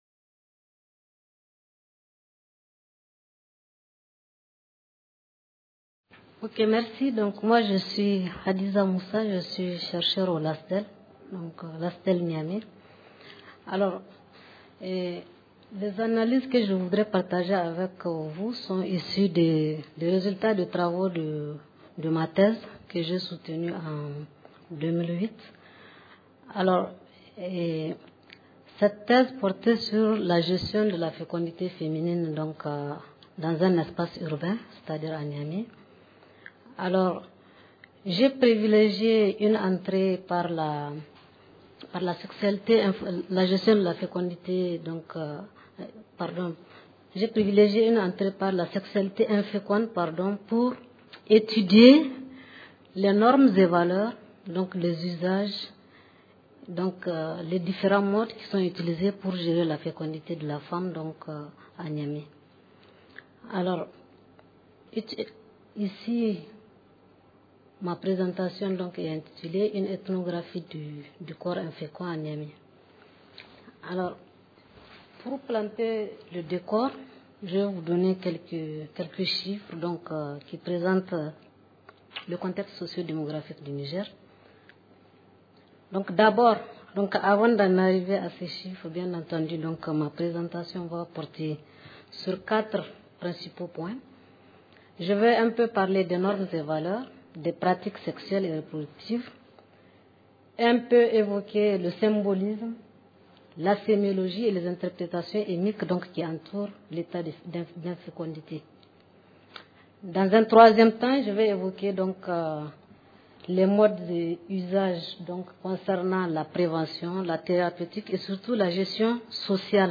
Réduction de la mortalité maternelle - Dakar 2010 : Une ethnographie du corps infécond à Niamey (Niger) Conférence enregistrée dans le cadre du Colloque International Interdisciplinaire : Droit et Santé en Afrique.